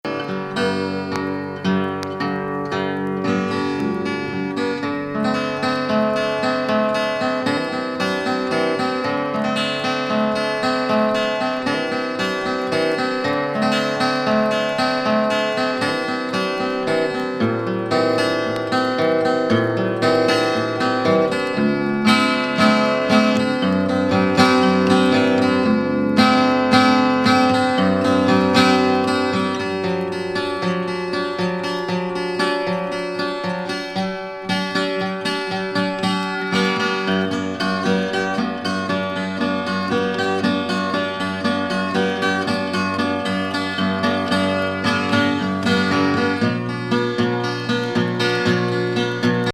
ハワイアン・メロウAOR。哀愁ギター・フレーズにグッとくるハワイアン・レゲエ
リンガラ的アプローチのトロピカル・ナンバー